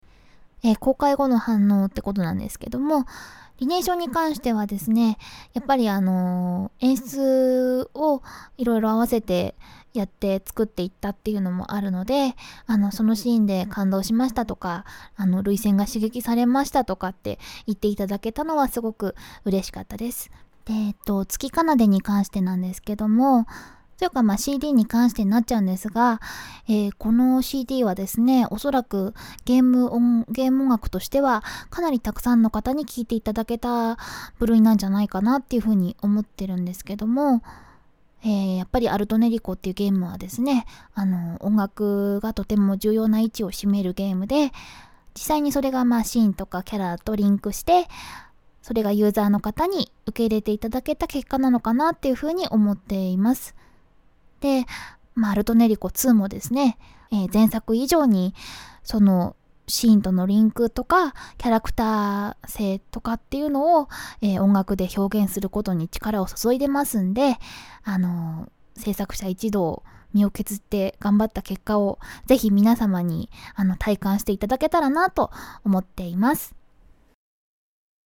歌い手さんには、霜月はるかさんにインタビューをさせていただき ました。
▼霜月さんからのメッセージ